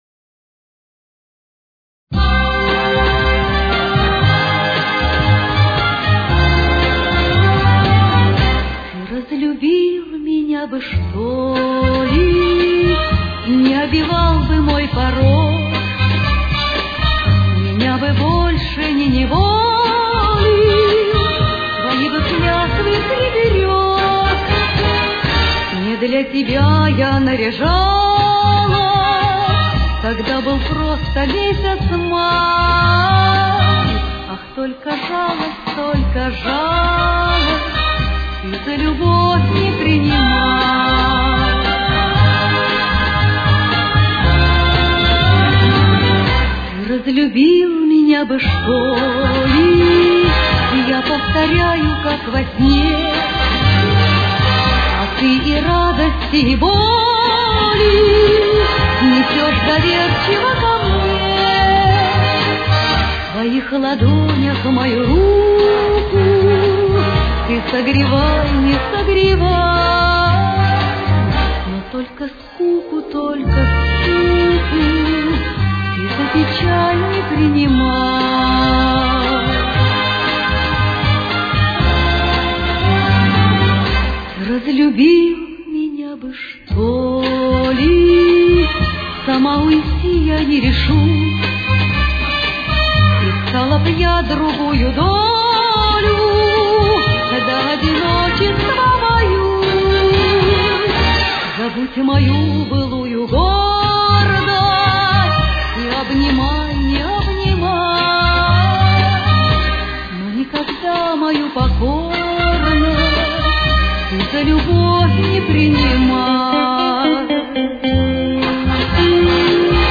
с очень низким качеством